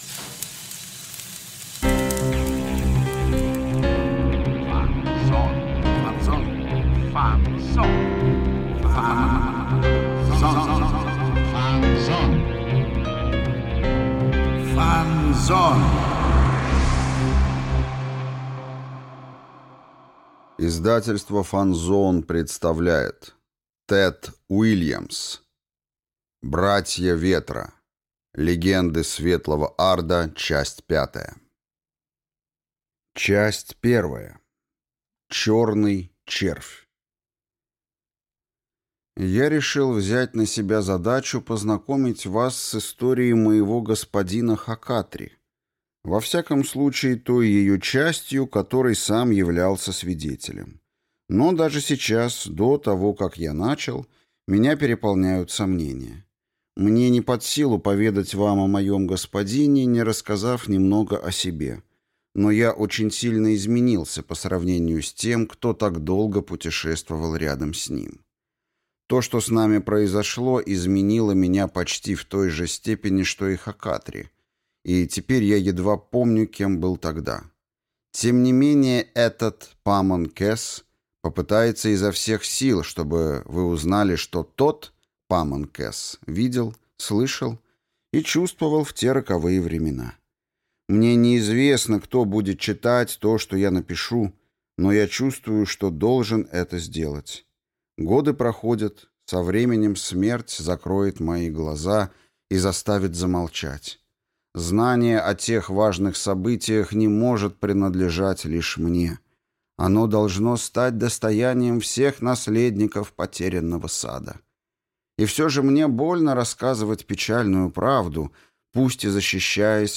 Аудиокнига Братья ветра. Легенды Светлого Арда | Библиотека аудиокниг